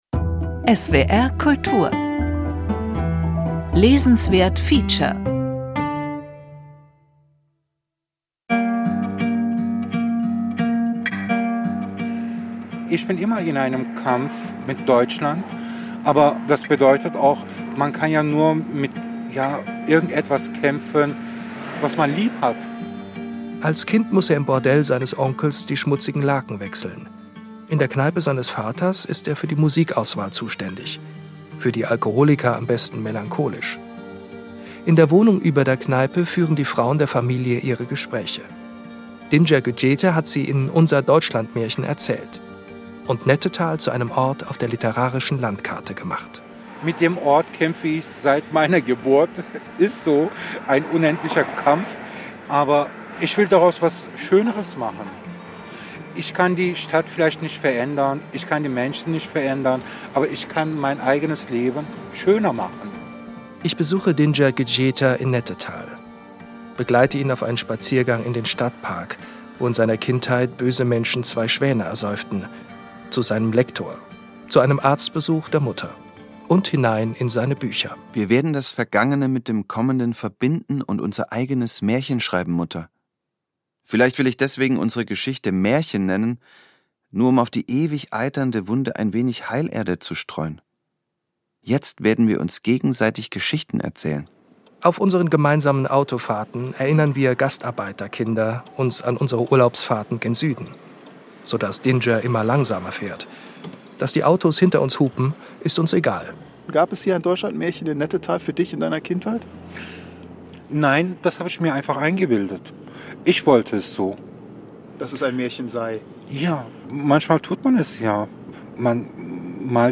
Dinçer Güçyeter macht Nettetal mit „Unser Deutschlandmärchen“ literarisch sichtbar. Im Gespräch